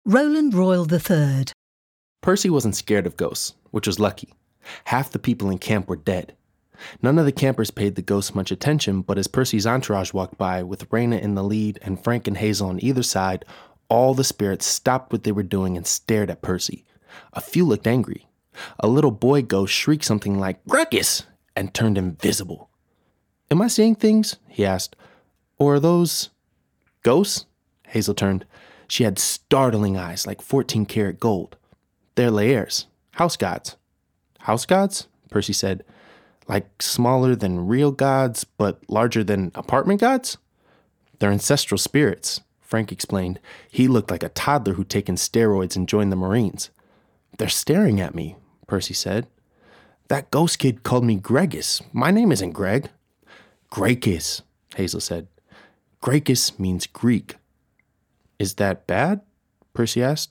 Description: American: confident, contemporary, authentic
Age range: 20s - 30s
Commercial 0:00 / 0:00